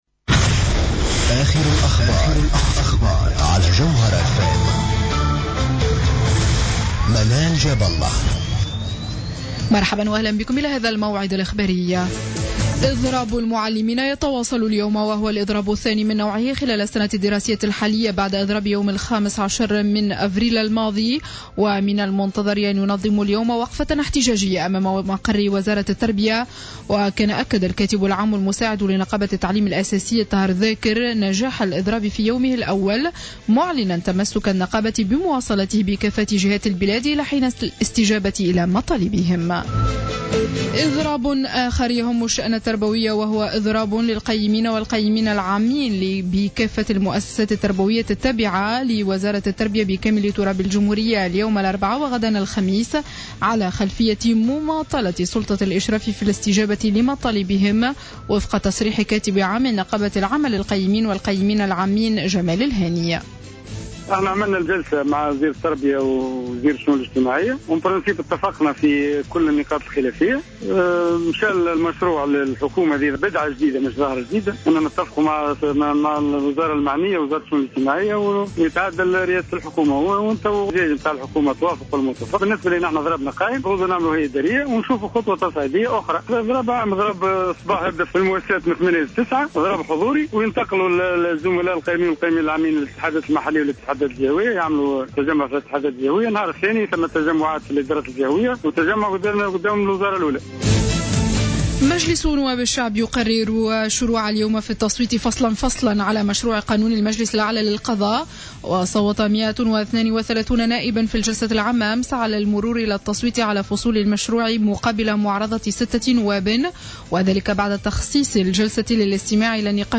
نشرة أخبار منتصف الليل ليوم الأربعاء 13 ماي 2015